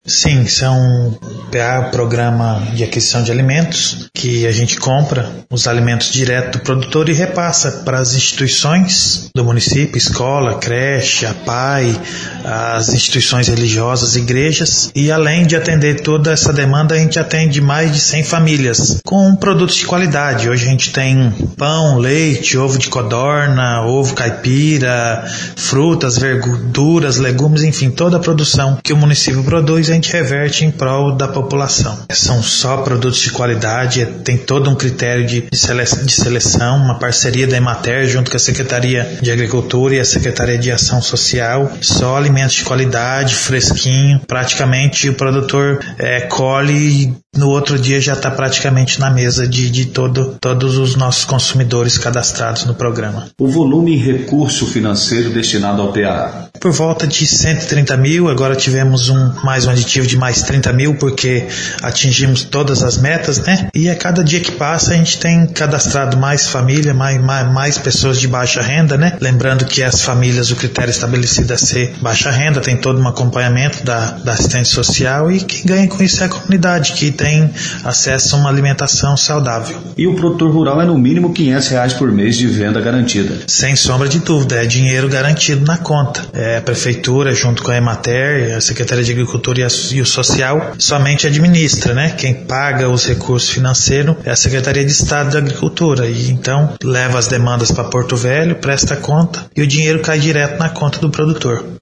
Prefeito Eduardo Bertoletti (MDB) sendo entrevitado
O prefeito gravou entrevista falando a respeito do programa.